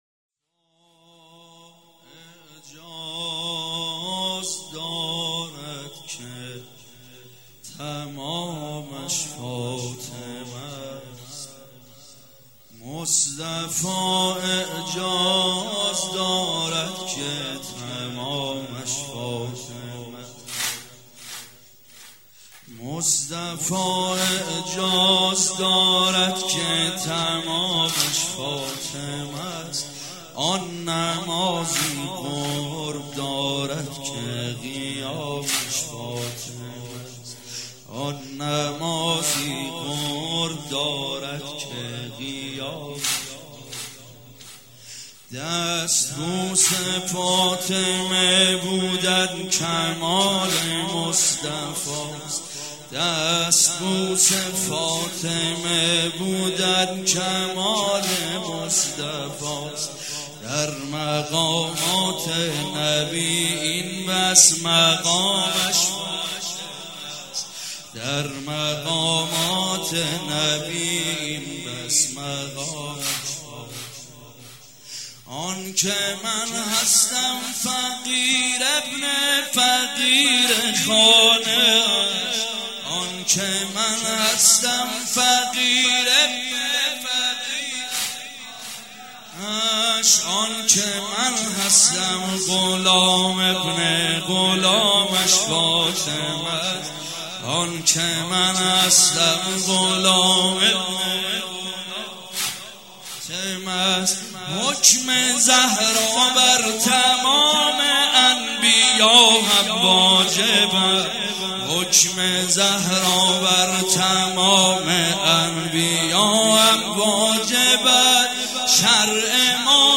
مراسم شب اول دهه فاطمیه دوم
مداح
مراسم عزاداری شب اول